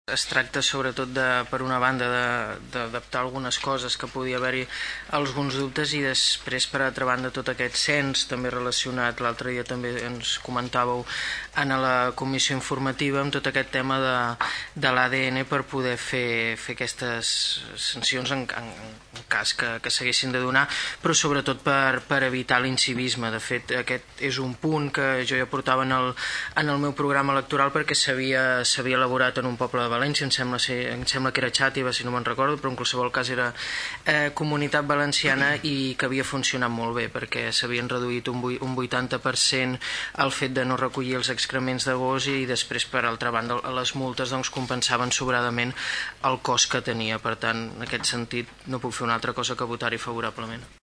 El regidor del PP, Xavier Martín, també va votar a favor d’aquest punt. Defensava la importància d’acabar amb l’incivisme al voltant dels excrements de gossos al carrer.